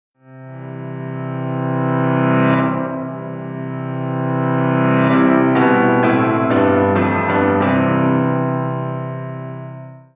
Track 13 - Piano 02.wav